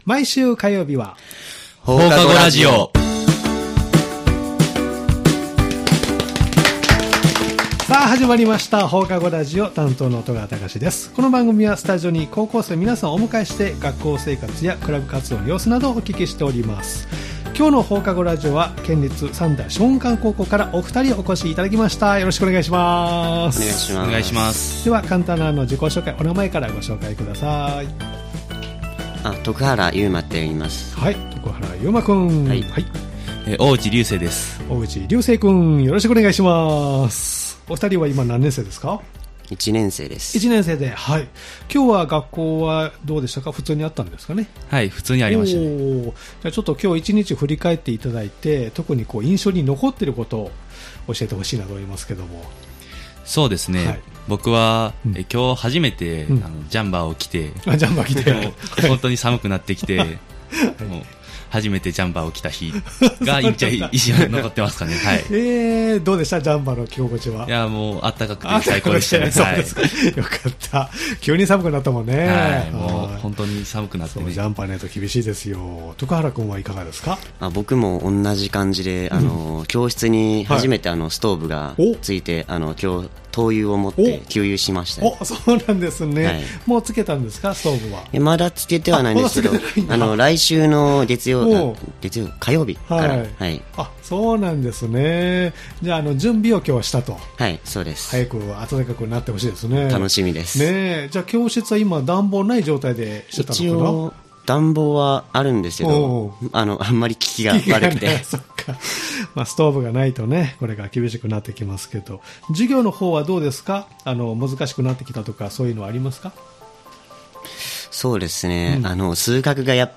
毎回高校生の皆さんをスタジオにお迎えして、学校生活、部活、学校行事などインタビューしています（再生ボタン▶を押すと放送が始まります）